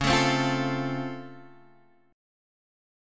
Db+M7 chord